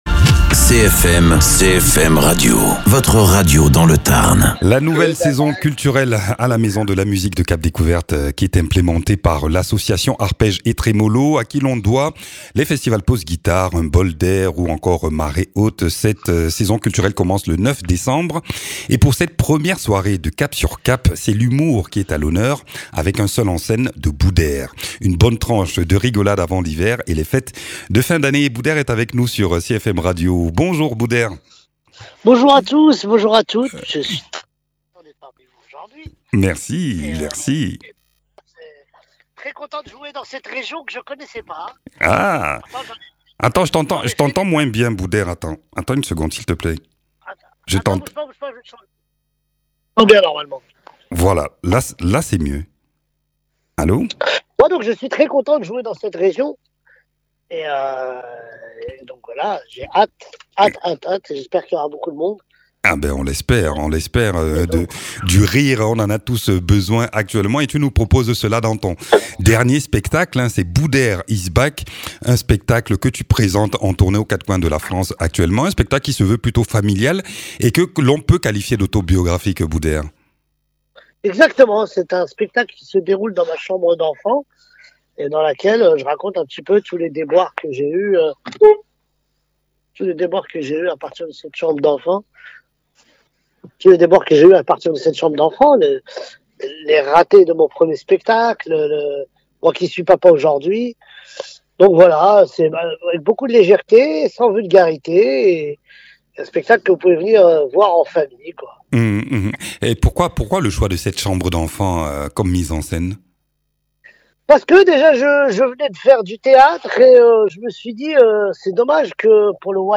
Interviews
Invité(s) : Booder, comédien et humoriste.